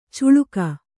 ♪ cuḷuka